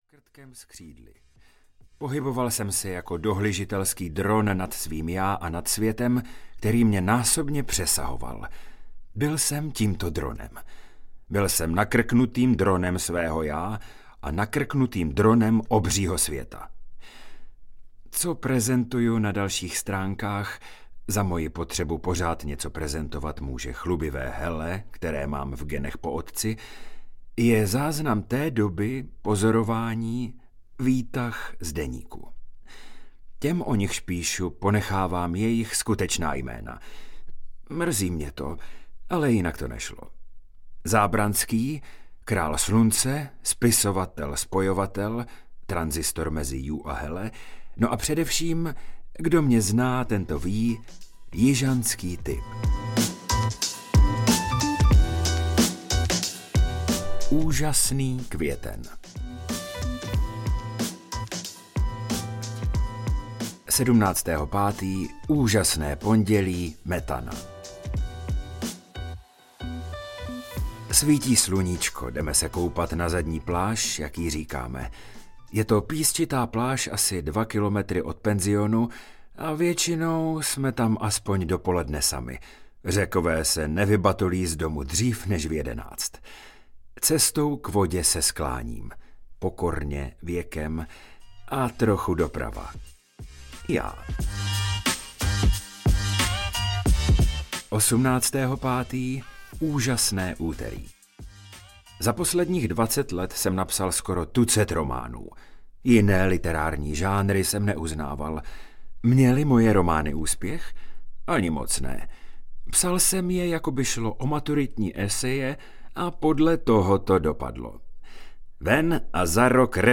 Jů a Hele audiokniha
Ukázka z knihy